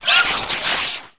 pain100.wav